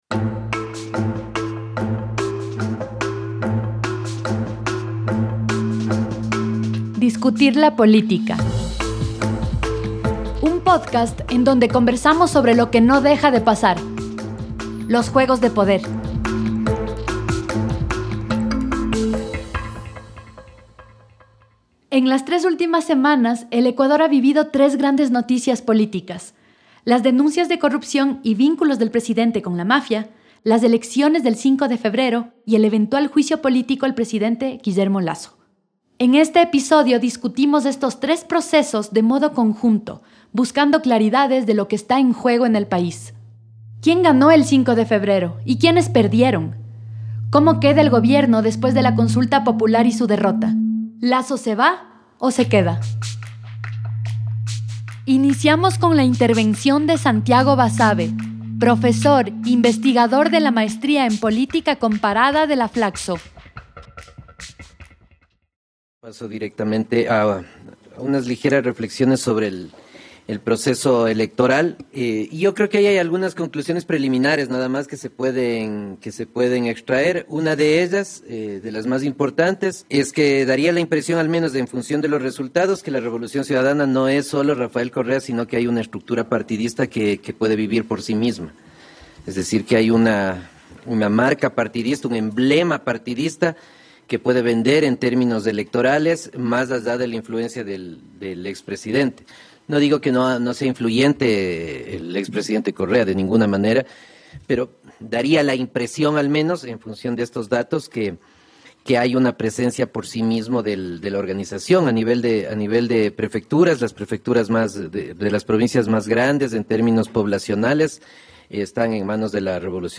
Formato El segundo martes de mes se organiza un conversatorio sobre un tema de coyuntura social, económica y/o política.
Podcast Con base en el conversatorio, se produce el podcast "Hablemos de Política" y se alimenta un repositorio de acceso abierto, con temas y datos sobre los temas de coyuntura.